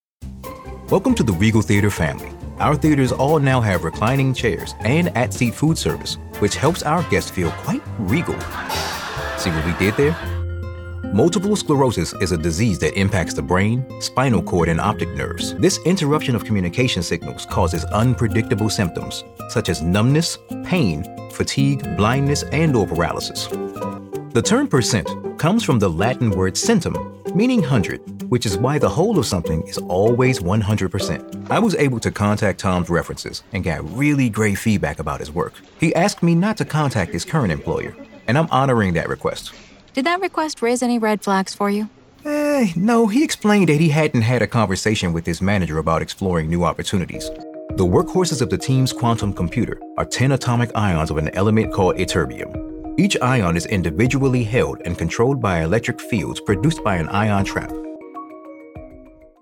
E-Learning
Meine Stimme ist hymnisch, geerdet und unverkennbar echt – eine Mischung aus Textur, Kraft und Seele, die das Publikum berührt und Ihre Botschaft hervorhebt.
Sennheiser 416, Neumann U87, TLM 103